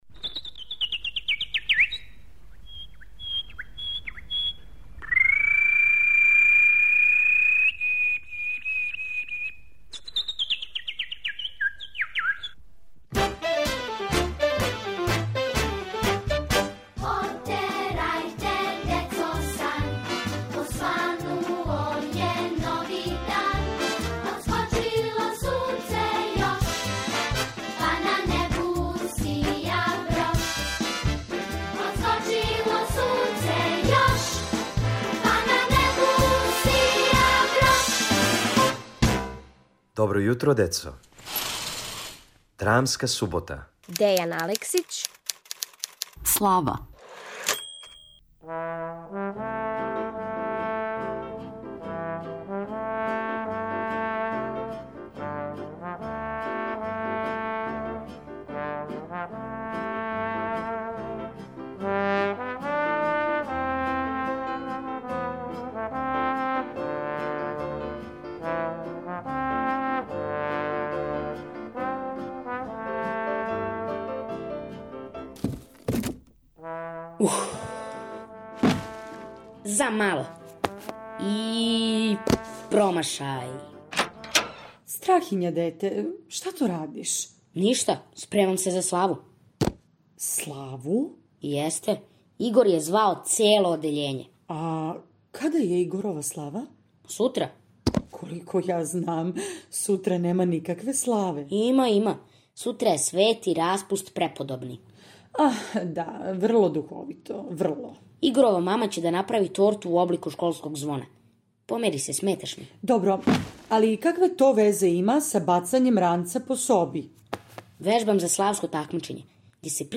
Сазнајте каква је то слава "свети распуст" и како се она прославља, у духовитој краткој драми Дејана Алексића.